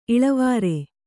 ♪ iḷavāre